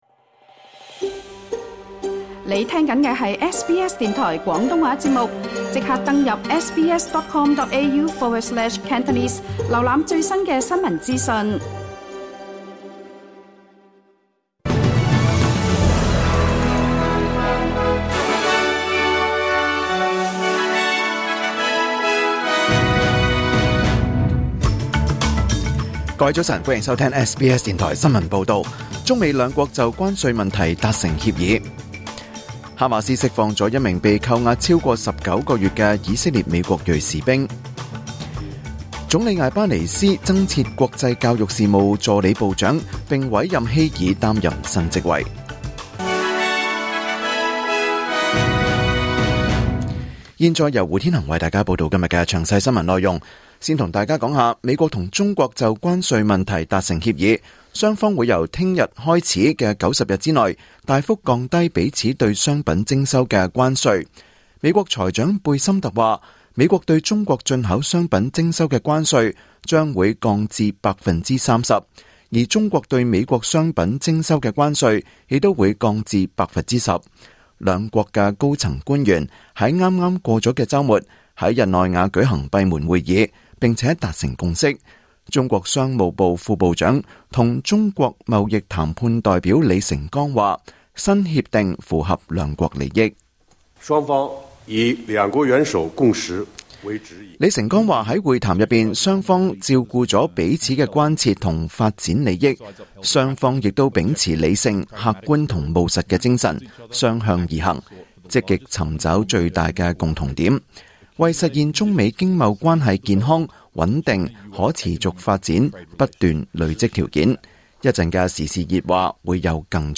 2025年5月13日SBS 廣東話節目九點半新聞報道。